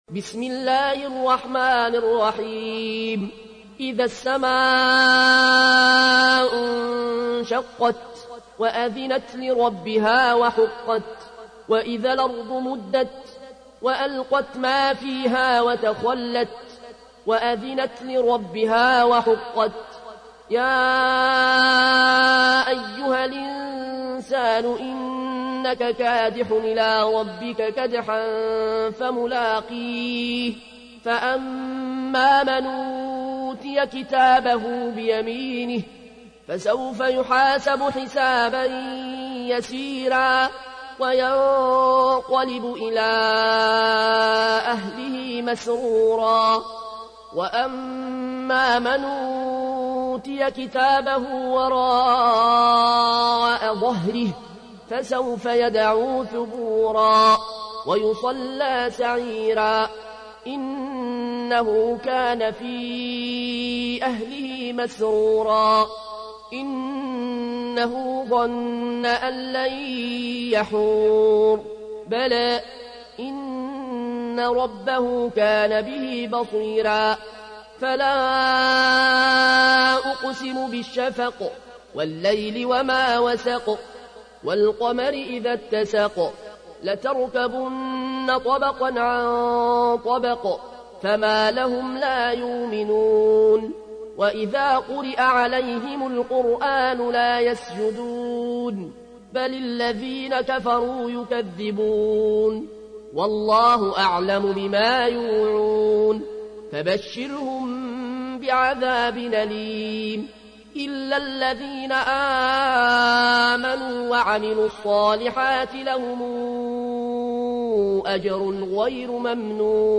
تحميل : 84. سورة الانشقاق / القارئ العيون الكوشي / القرآن الكريم / موقع يا حسين